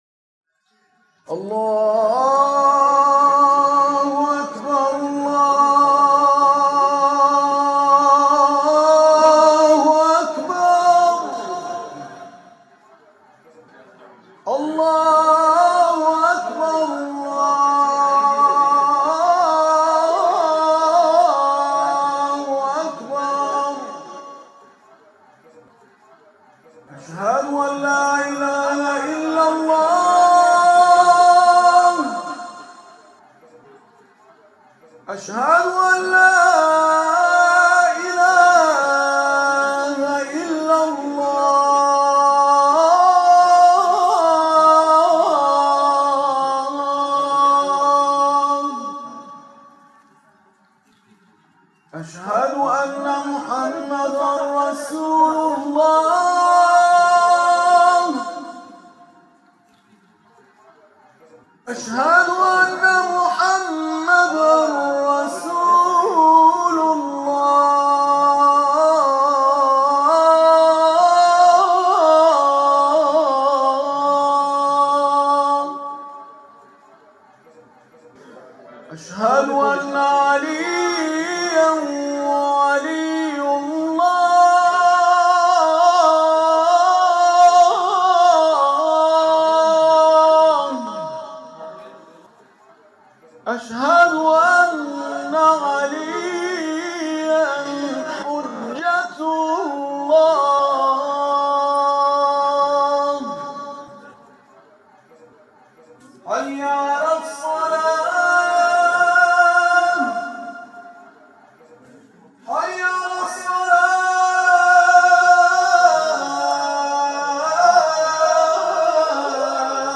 اذانی در مقام عجم
تلاوت اذانگاهی